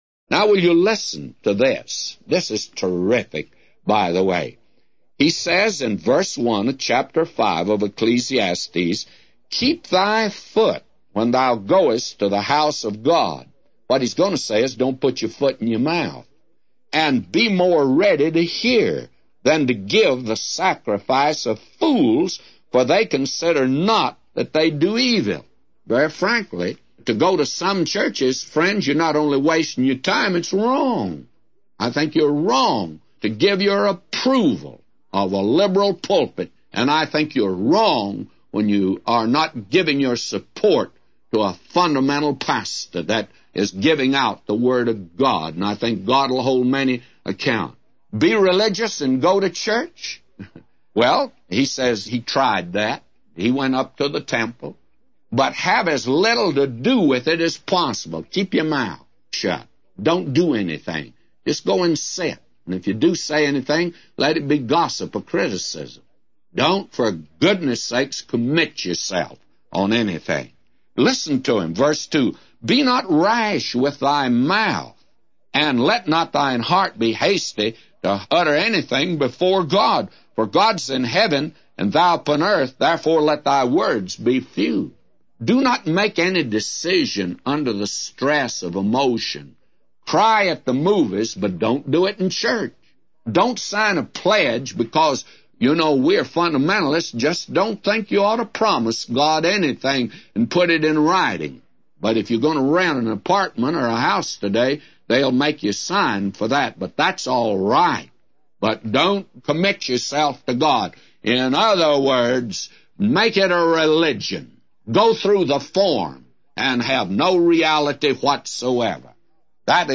A Commentary By J Vernon MCgee For Ecclesiastes 5:1-999